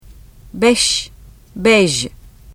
şは無声後部歯茎摩擦音[ʃ]で、jは有声後部歯茎摩擦音[ʒ]で実現します。